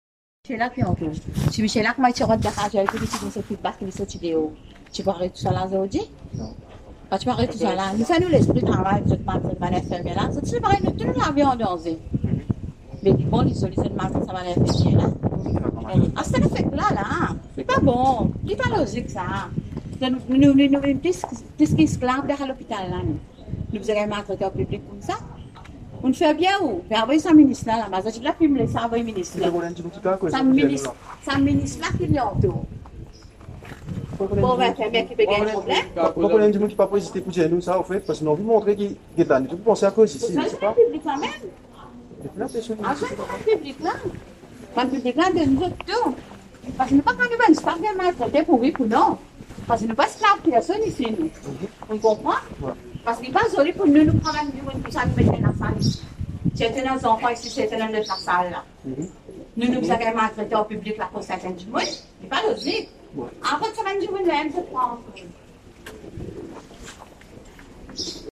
Une infirmière croisée dans le couloir, toujours énervée, s’exprime à ce propos…